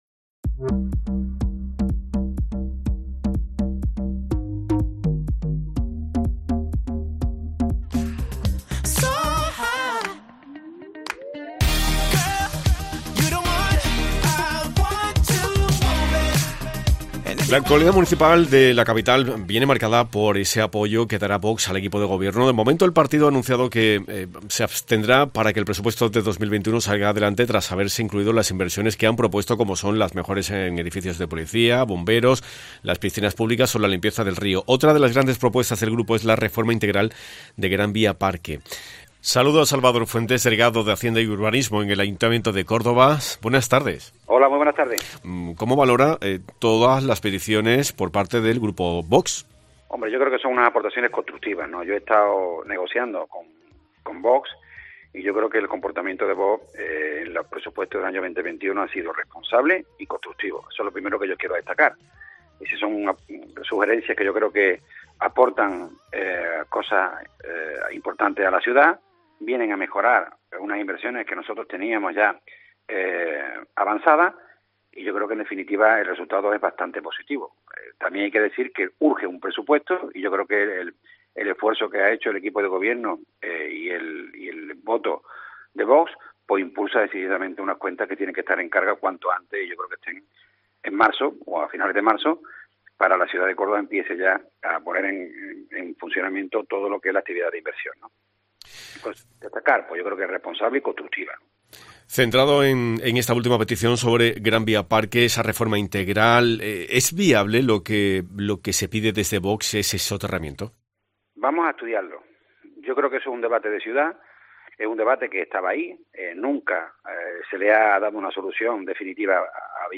Salvador Fuentes, delegado de Hacienda y Urbanismo, ha asegurado en COPE que las aportaciones de Vox han sido constructivas y que "después de las reuniones que hemos mantenido me gustaría dejar claro que el comportamiento de este grupo ha sido responsable aportando cosas importantes a la ciudad y que vienen a mejorar unas inversiones que nosotros teníamos ya avanzadas.